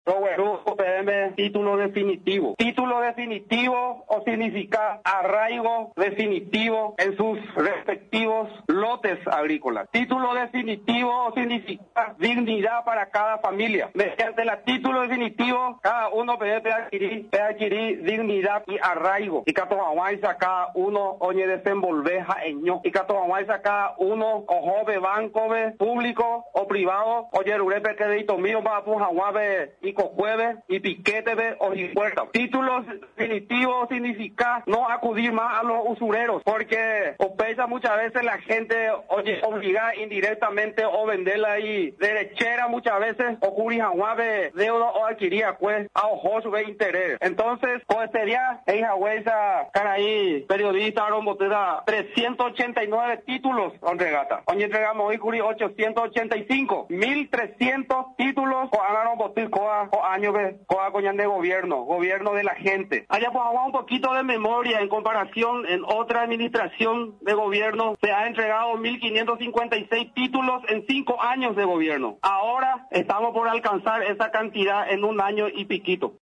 En un acto realizado en la sede del Instituto Nacional de Desarrollo Rural y de la Tierra (INDERT), de Caaguazú, el Gobierno Nacional entregó títulos de propiedad a unos trescientos ochenta beneficiarios de la Reforma Agraria.